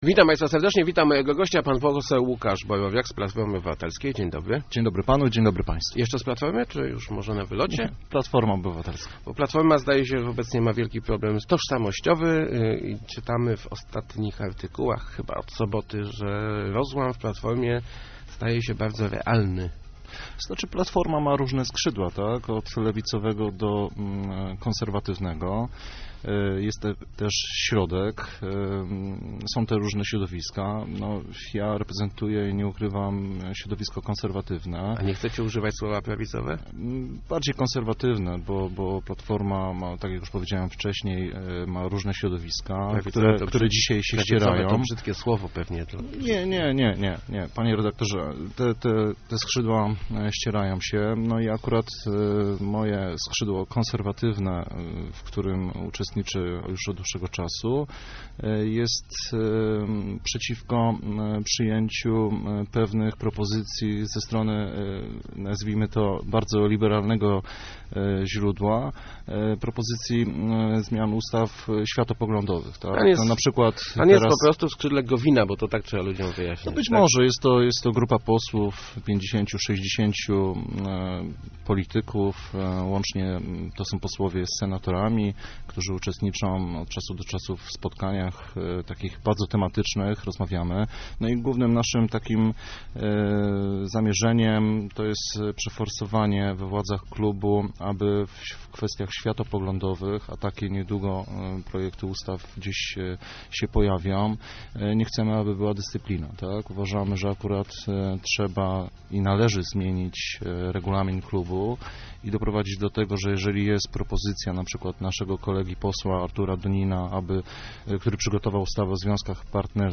O roz�amie w Platformie nie ma mowy, ale jest dyskusja o zniesieniu dyscypliny w g�osowaniu spraw �wiatopogl�dowych - mówi� w Rozmowach Elki pose� �ukasz Borowiak.